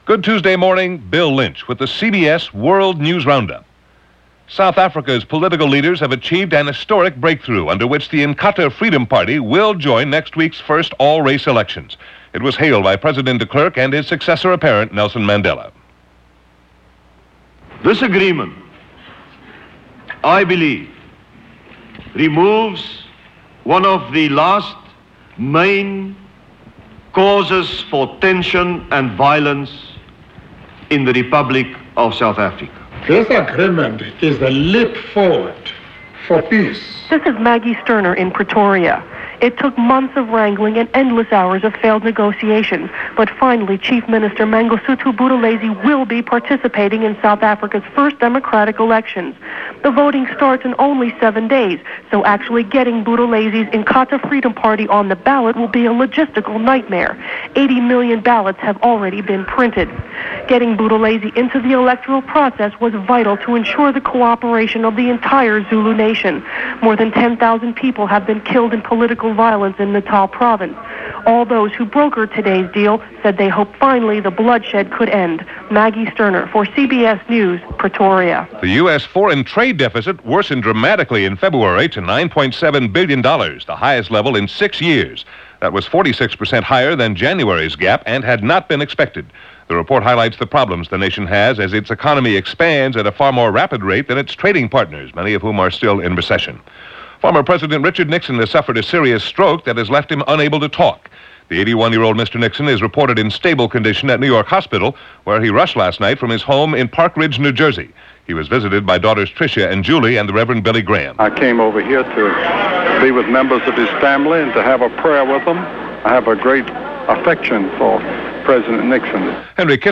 And that’s only a sample of what went on, this April 18, 1994 as presented by The CBS World News Roundup.